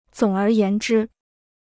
总而言之 (zǒng ér yán zhī): in short; in a word.